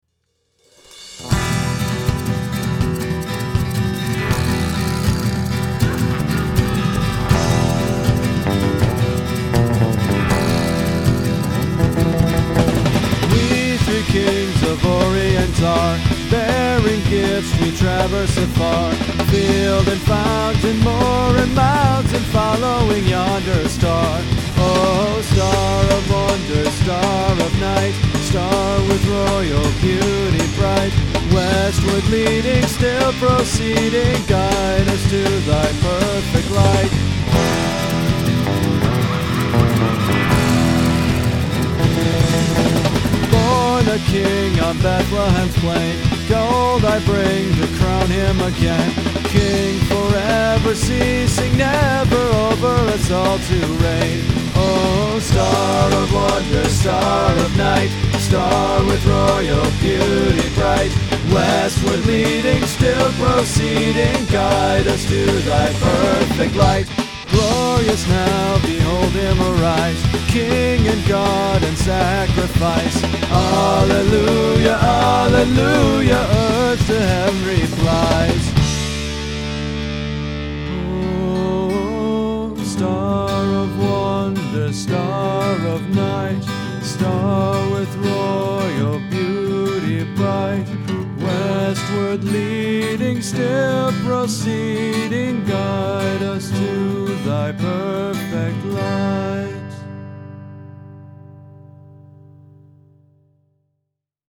This tune is normally in 6/8 time, but I forced it into a fast-paced 4/4 here… except for the last chorus, which I decided to play straight. The surf-rock/espionage/James Bond part… well, to be honest, I don’t know where that idea came from, but I’m glad it’s there. It also served as a flimsy excuse to use a vibra-slap.